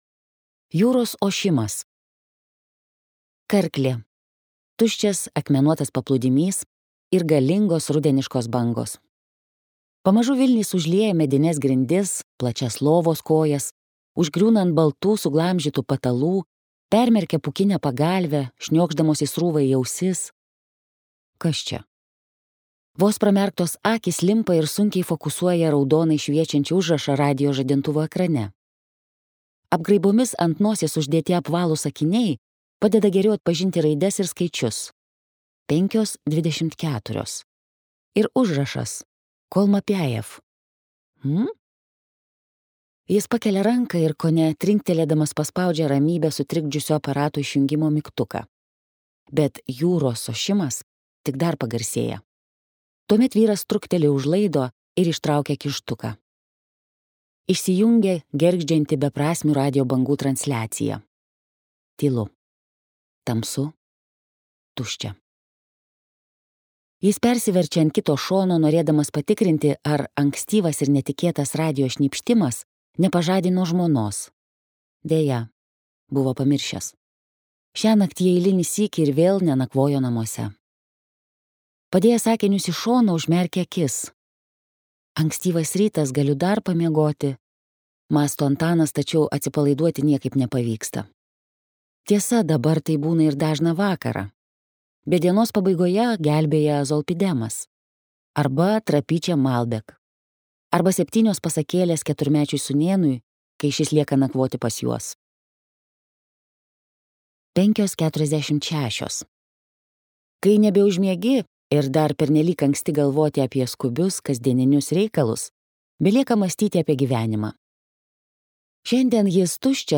Poliklinika arba netikėti medžiai | Audioknygos | baltos lankos